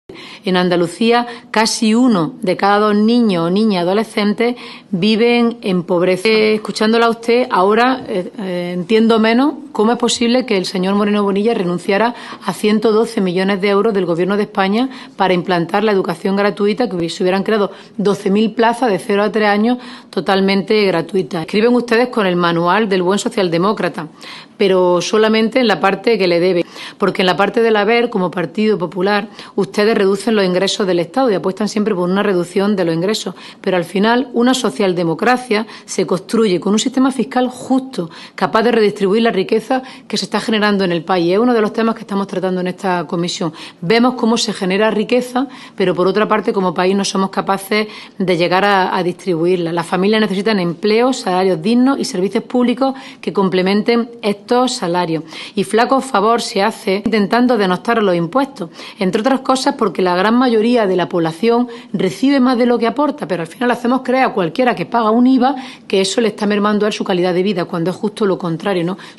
Cobo hizo estas declaraciones en una Comisión del Congreso en la que se debate la puesta en marcha de un Pacto de Estado contra la Pobreza Infantil.